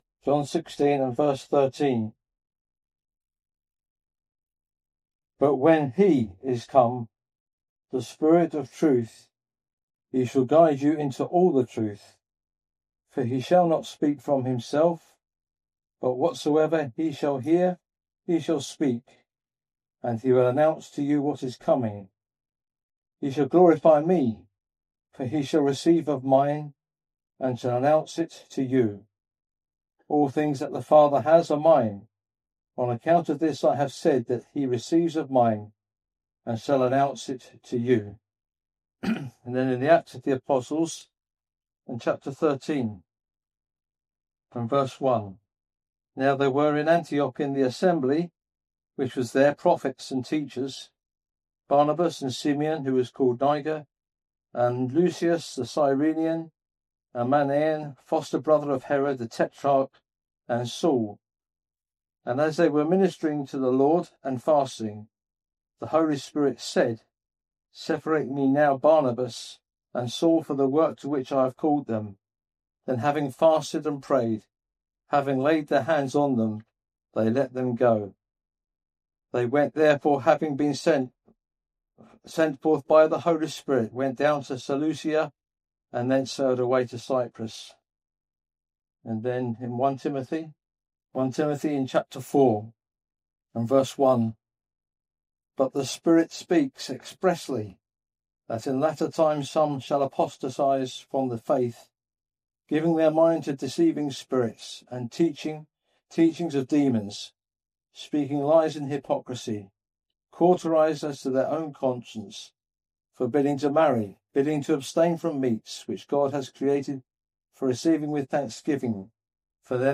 In this ministry word you will hear about The Speaking of the Holy Spirit.